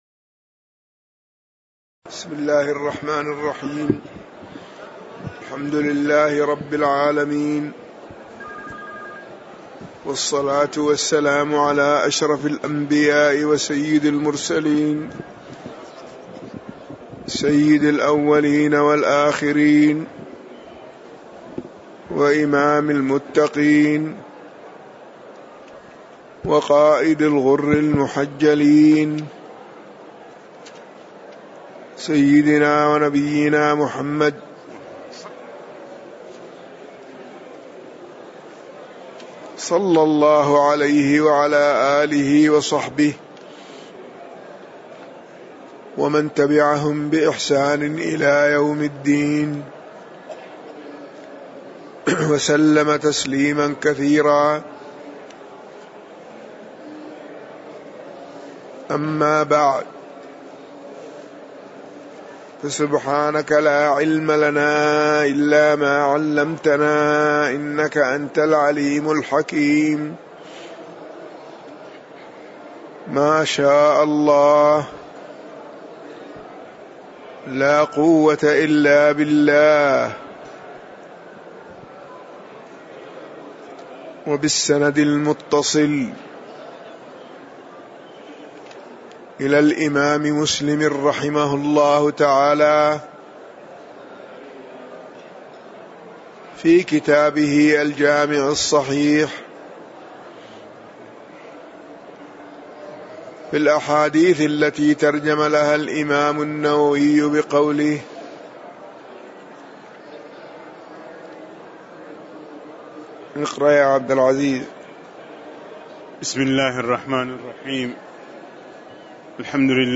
تاريخ النشر ١٥ محرم ١٤٣٨ هـ المكان: المسجد النبوي الشيخ